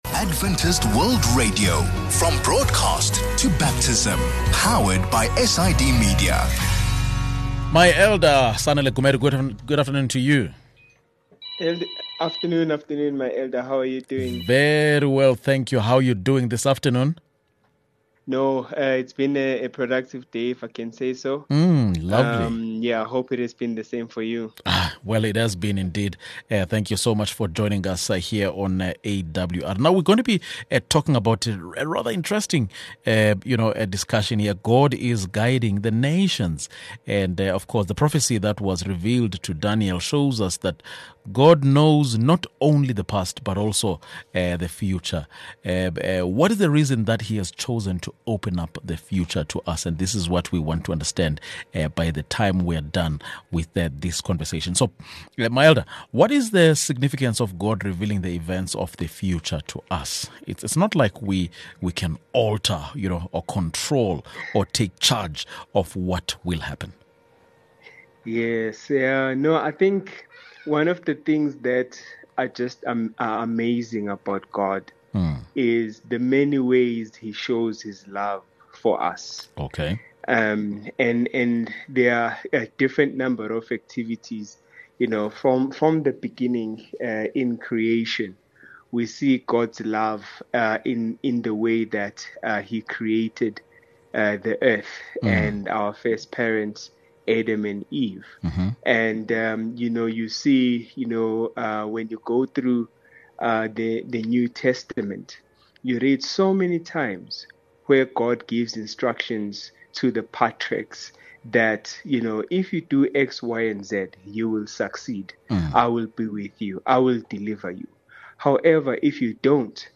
23 Jan VOP Lesson | God is Guiding the Nations